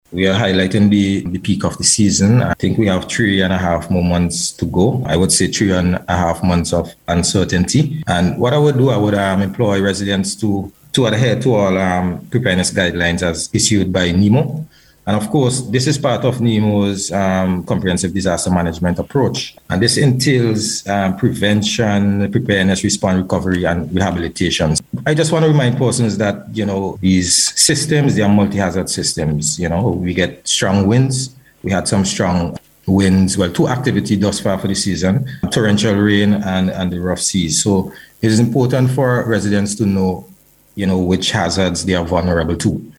He made this appeal during the Face to Face program aired on NBC Radio this week.